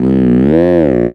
Cri de Chelours dans Pokémon Soleil et Lune.